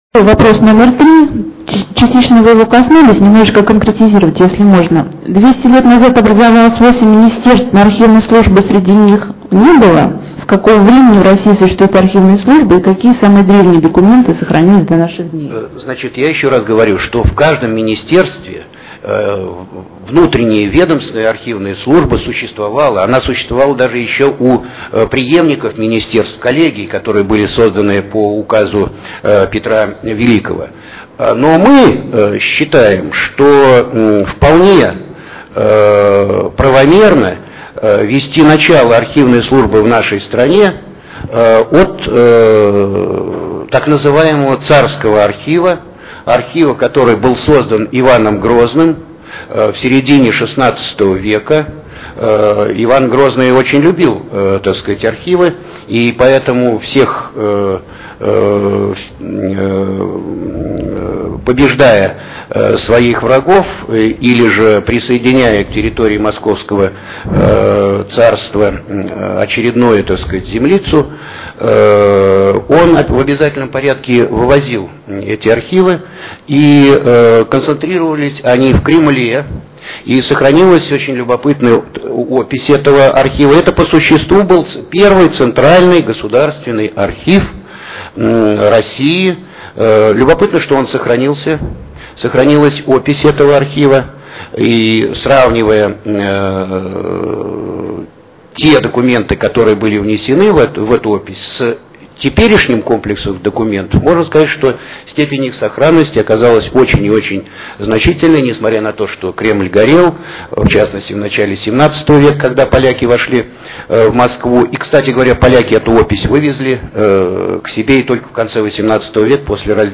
Интернет-конференция